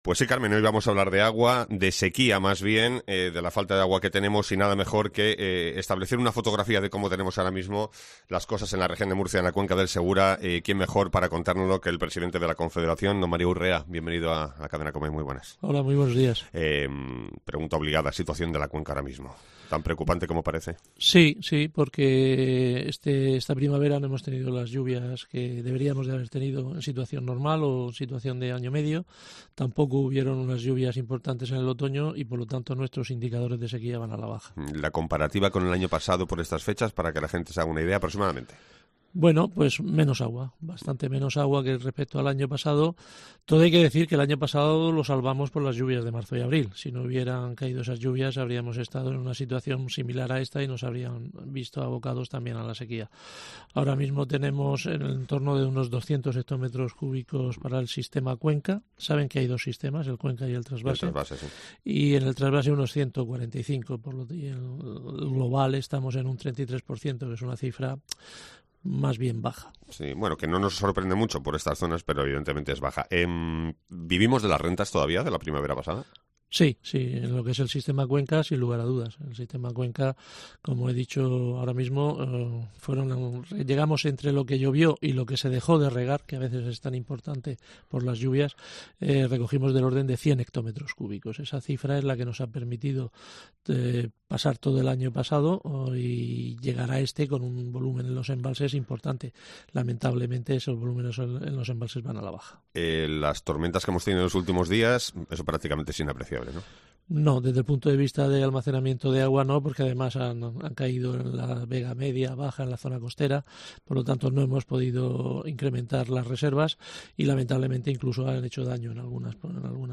Mario Urrea, presidente de la Confederación Hidrográfica del Segura
La situación en la cuenca del Segura es preocupante, según ha dicho hoy en COPE el presidente de la Confederacion Hidrográfica Mario Urrea.